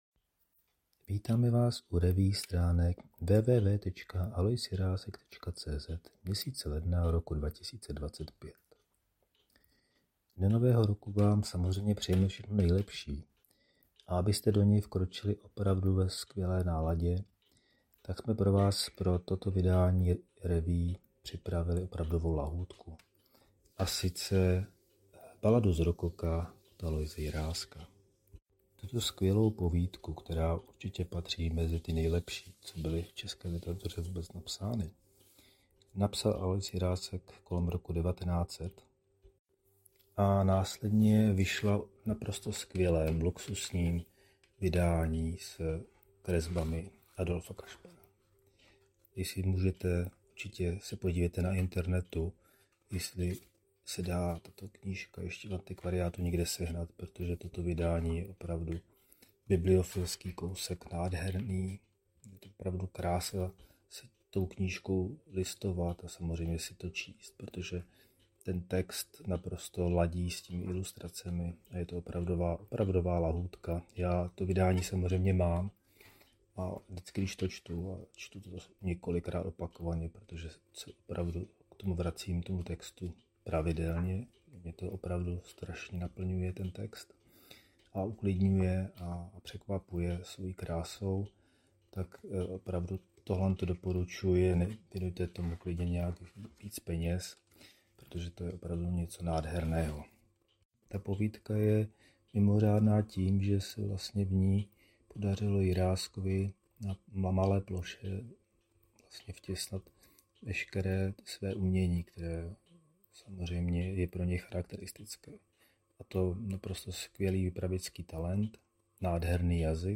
Nejprve si poslechněte zvukový úvod a pak se začtěte do jedné z nejlepších českých povídek vůbec, nádherné “Balady z rokoka” Aloise Jiráska.